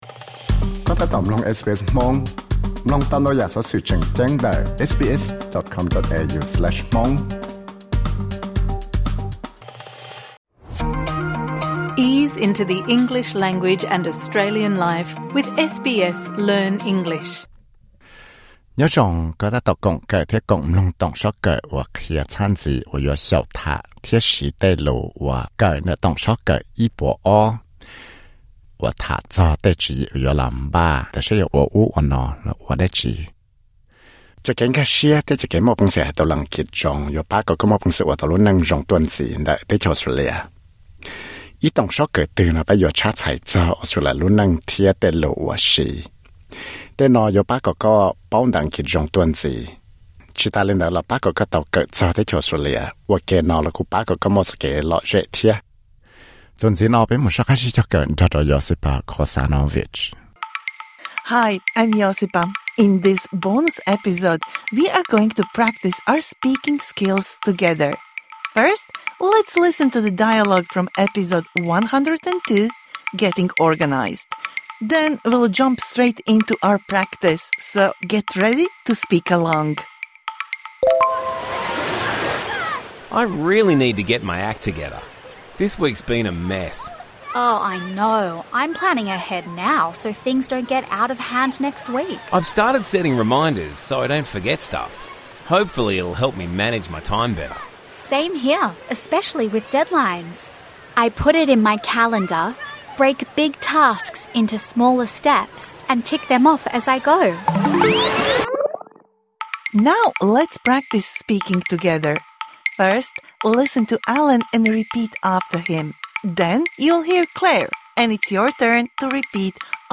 Learn the meaning of the phrases used in this dialogue